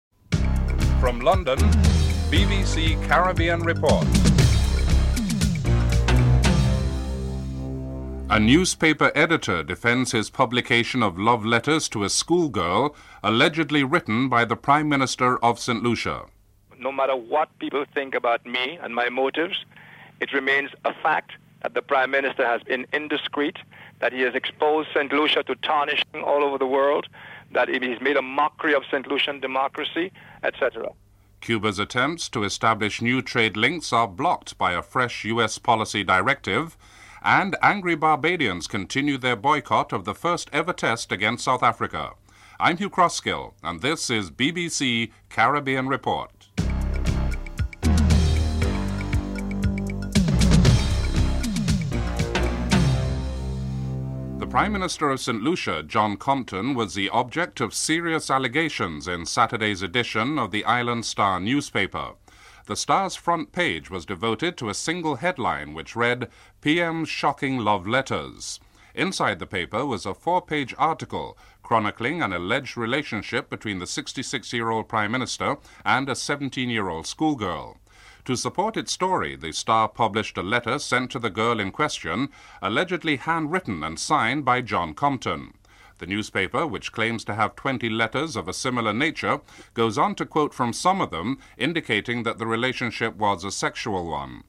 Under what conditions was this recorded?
Final segment ends abruptly.